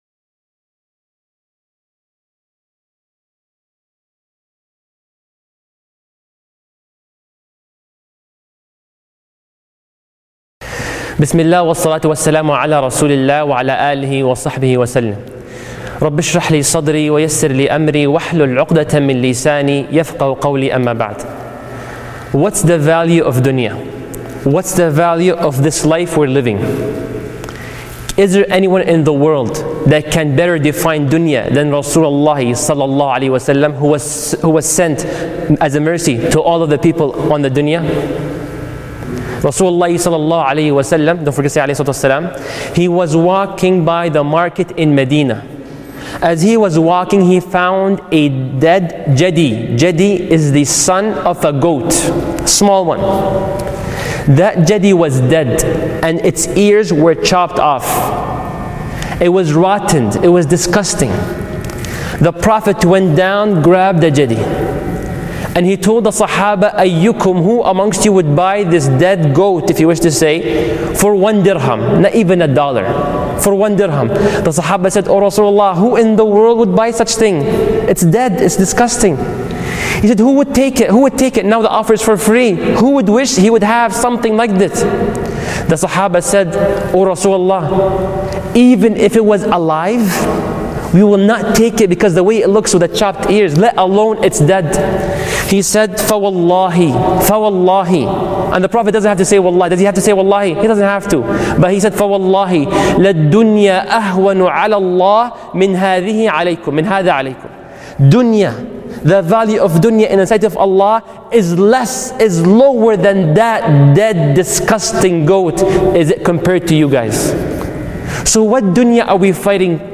This powerful lecture uses this hadith as a springboard to explore one of the most essential qualities for entering Paradise: the ability to forgive and let go of grudges, hatred, and envy, even when you have every right to be angry.